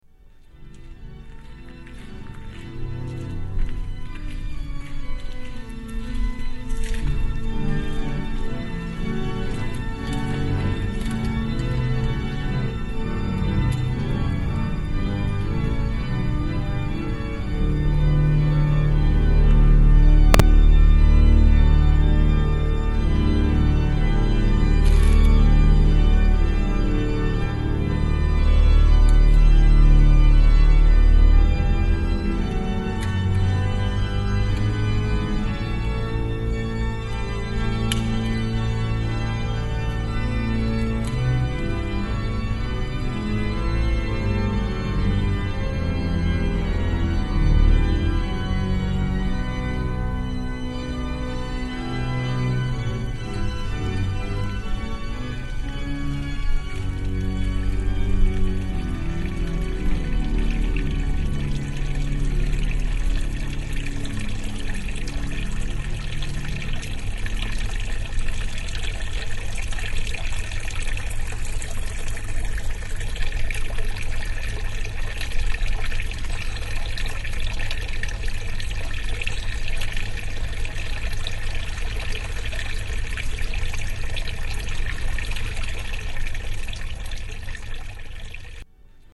St Vitus Church Organ and a fountain
Tags: Travel Sounds of Czech Republic Czech Republic Prague Vacation